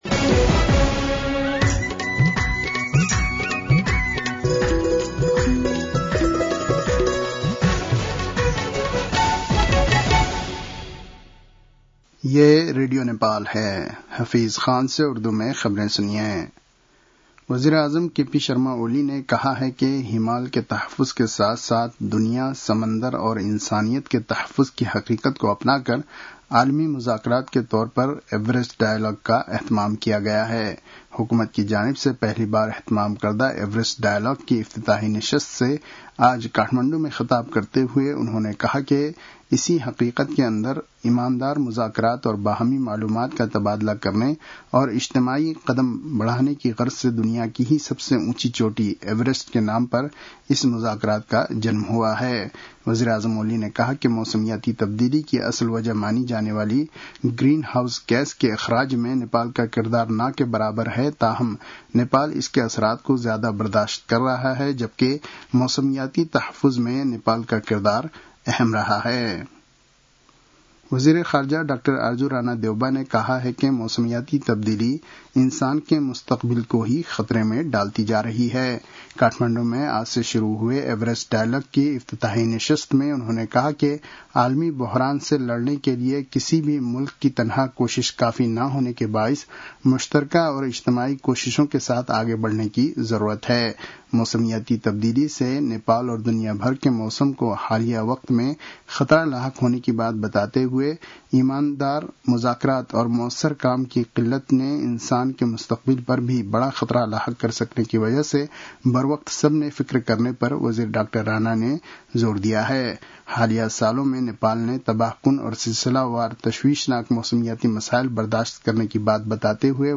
उर्दु भाषामा समाचार : २ जेठ , २०८२